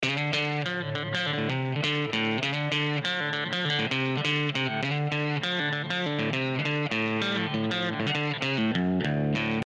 View attachment GuitarTone.mp3